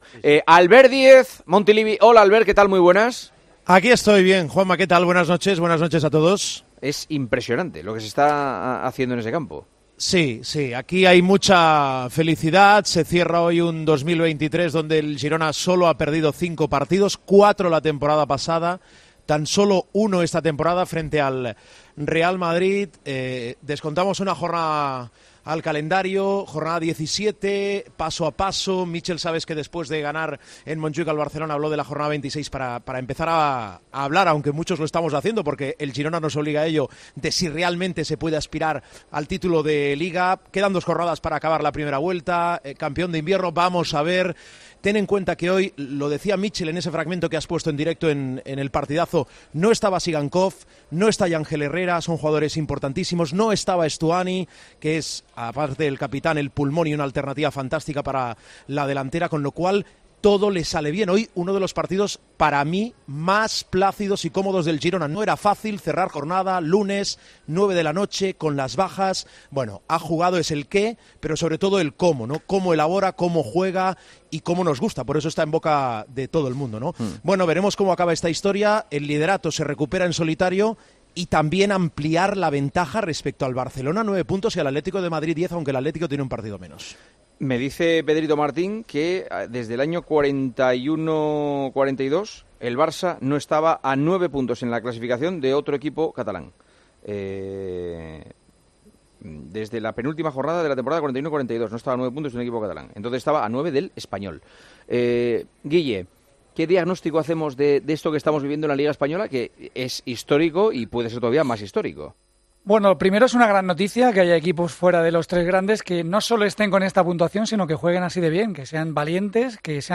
Los tertulianos de El Partidazo aseguraron estar muy sorprendidos con el equipo de Míchel y terminaron de perfilarlo como un claro candidato para llevarse el título de Liga.